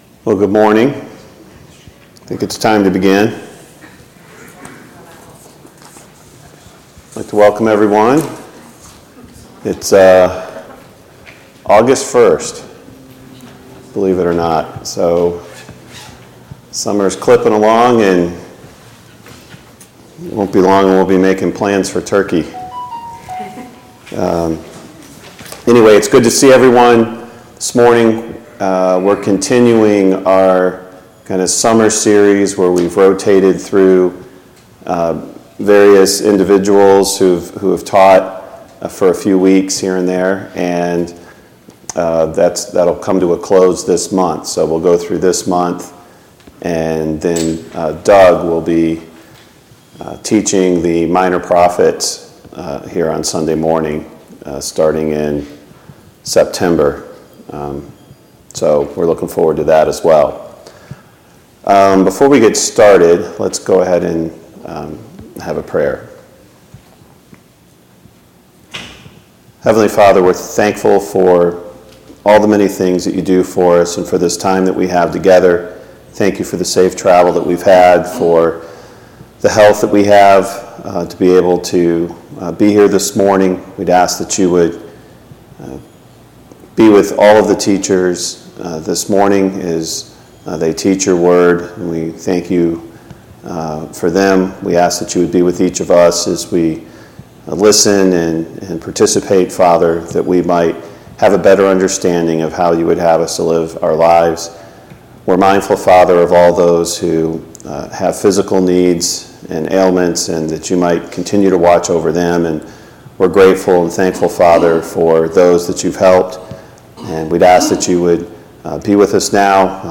Service Type: Sunday Morning Bible Class Topics: Christian Evidences